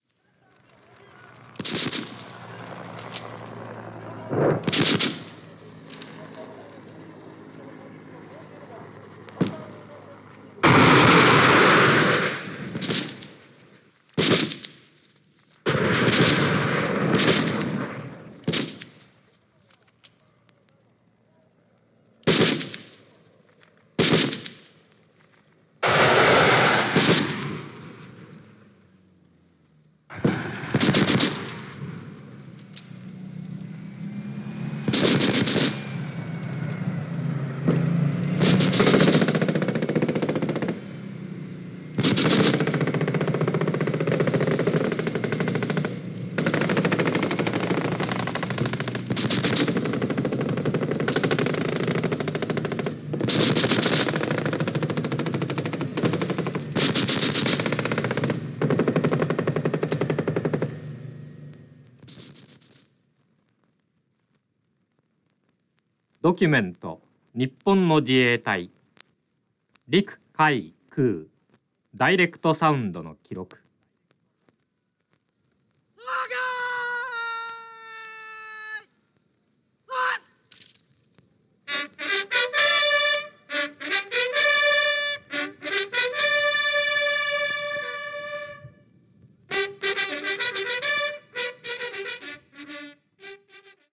"64式7.62mm自動小銃, 単発・連発射撃"
日本の自衛隊・演習のレコードです。